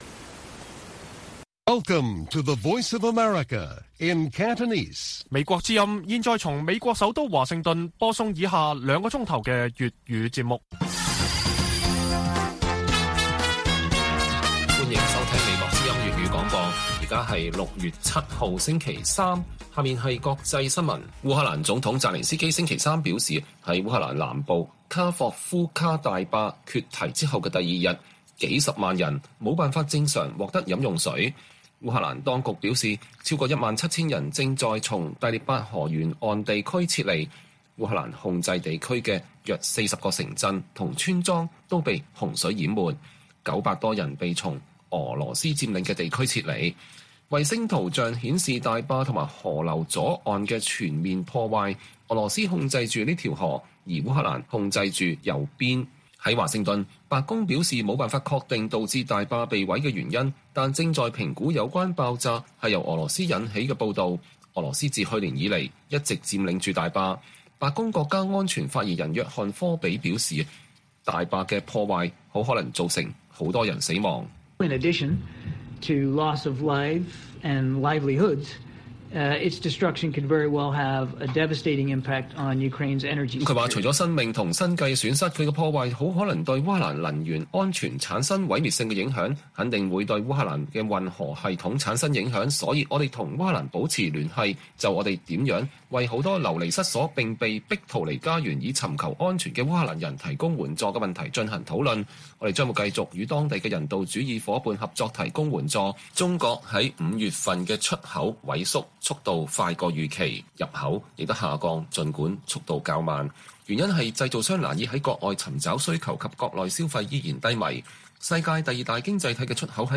粵語新聞 晚上9-10點: 白宮指責俄羅斯破壞烏克蘭大壩很可能造成“許多人死亡”